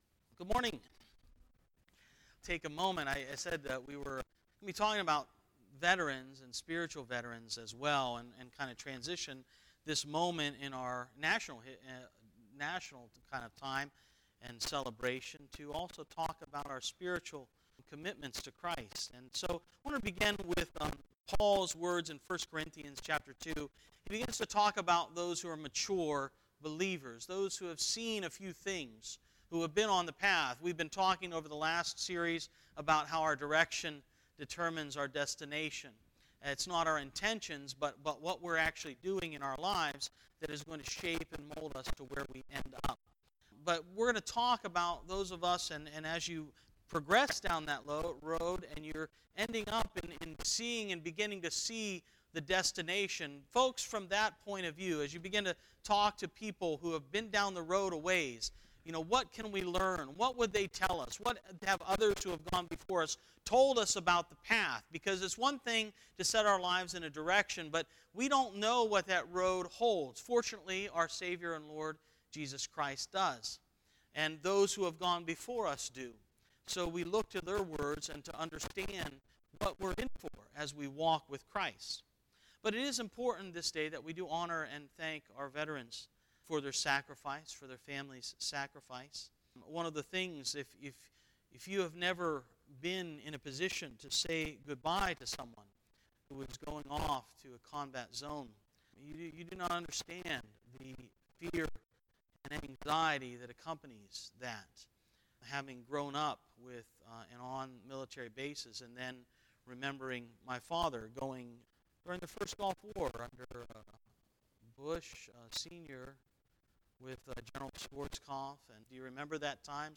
11-11-18 Sermon (Veteran's Day)